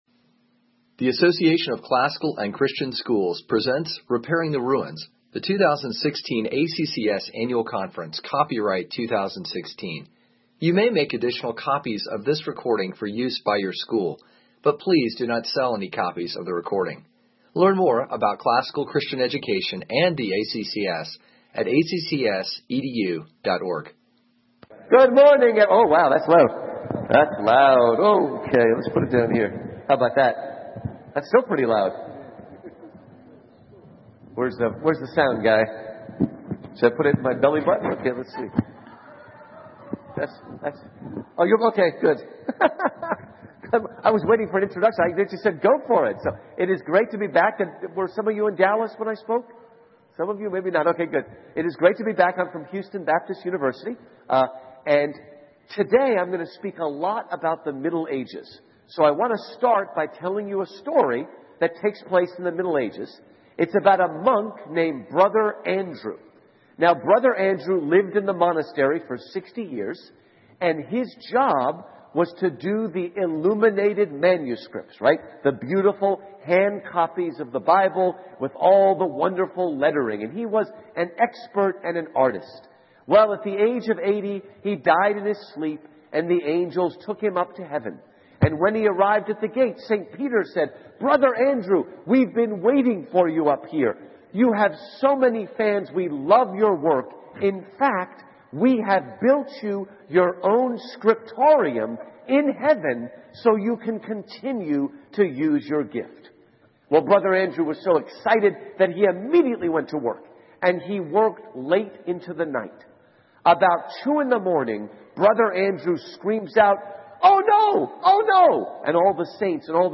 2016 Workshop Talk | 0:56:01 | All Grade Levels, General Classroom